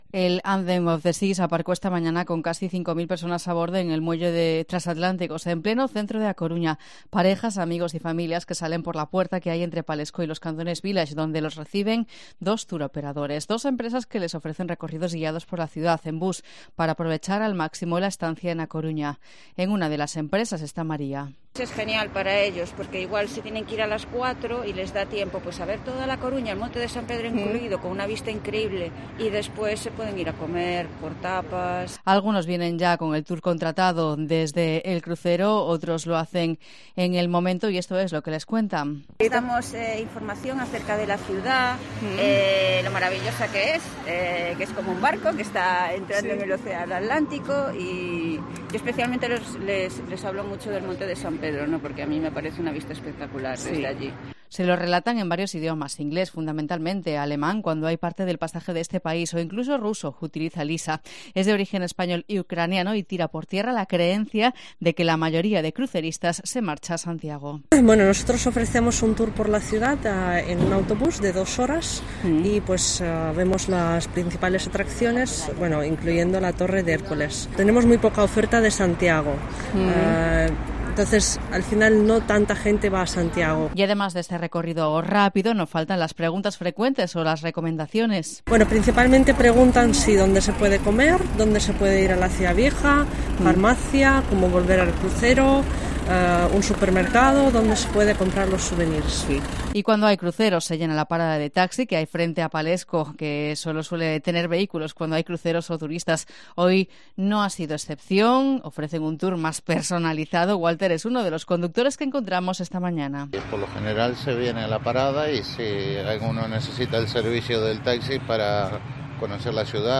Uno de ellos, un joven, comenta con un marcado acento británico que su plan es dar un paseo por la costa y probar las “tapas” a mediodía.